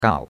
gao3.mp3